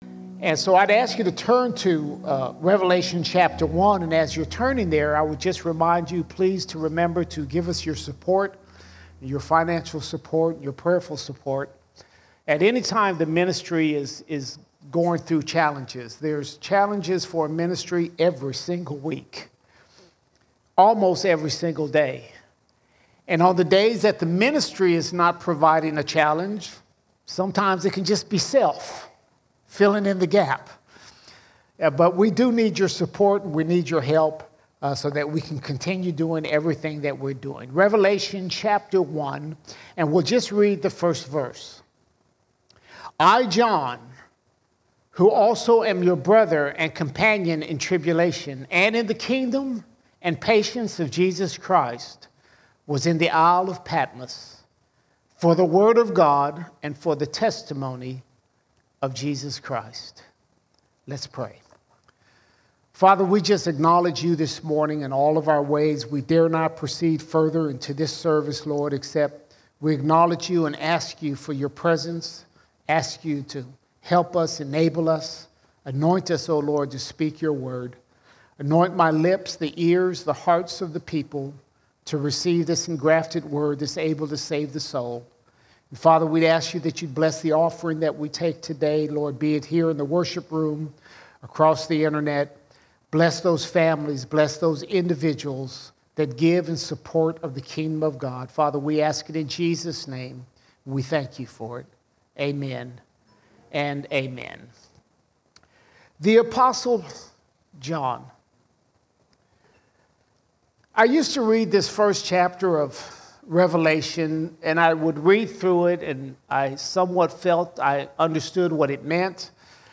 6 May 2024 Series: Sunday Sermons Topic: Jesus All Sermons Alpha and Omega Alpha and Omega In Revelation, Jesus is the Alpha and Omega.